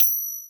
Triangle Rnb.wav